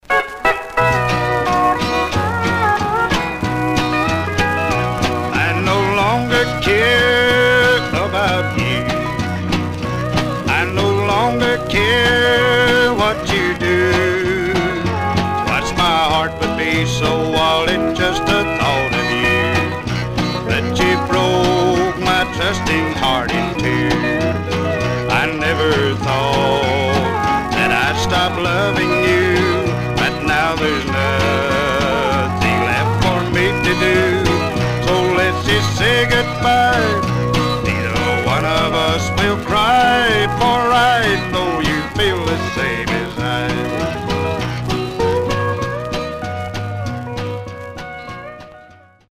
Some surface noise/wear
Mono
Country